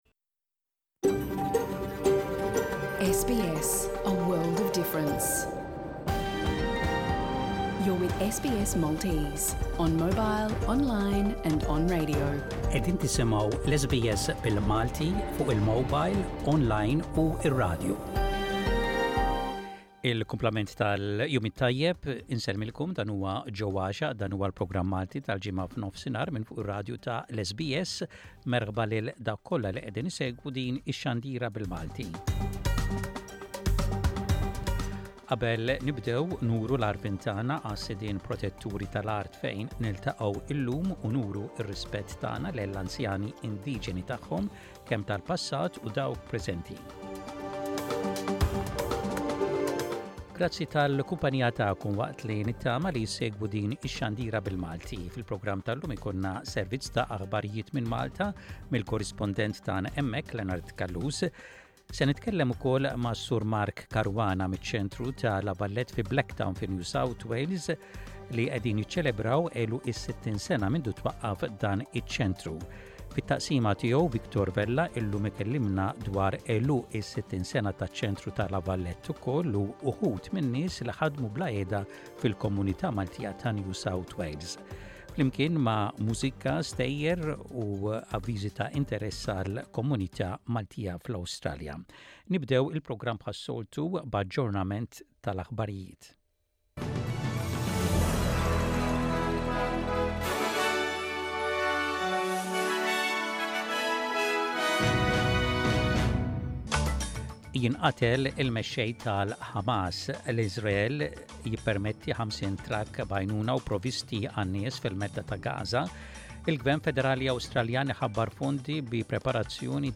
Jinkludi l-aħbarijiet mill-Awstralja